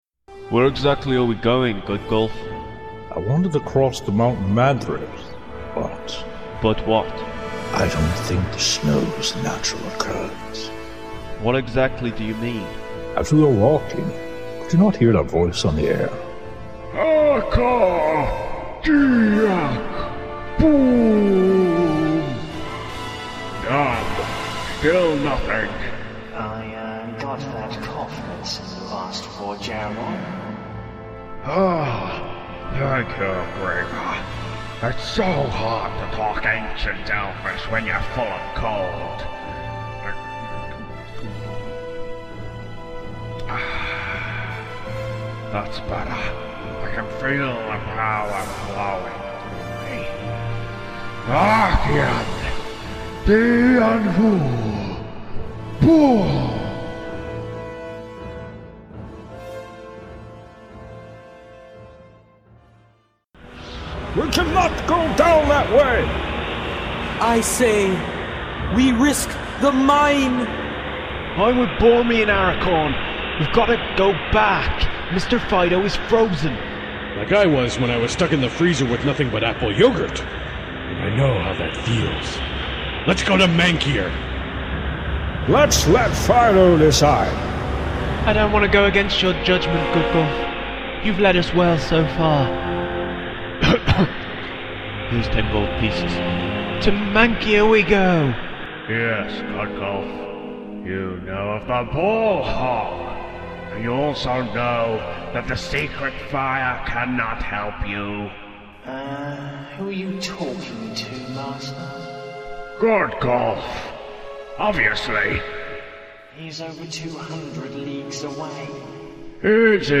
Random Insanity 2005- (Parody)
Involvment: Acting(Grimier)